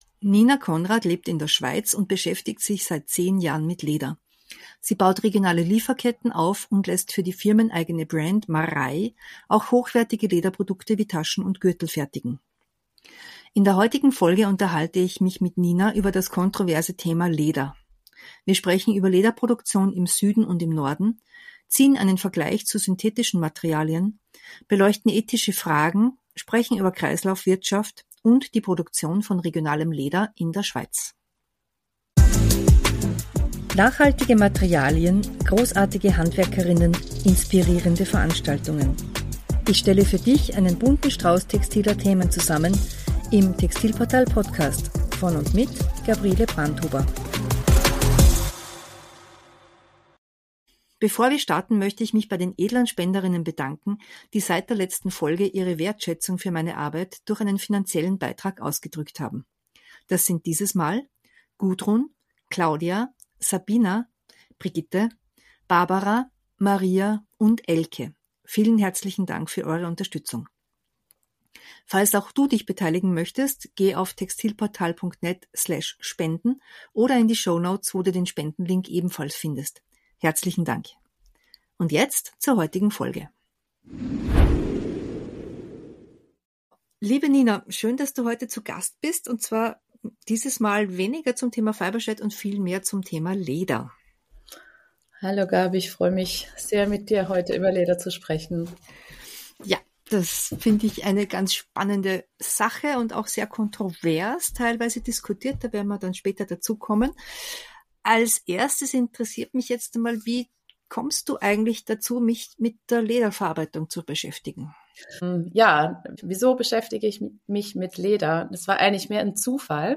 Ein Gespräch über das kontroverse Thema "Leder"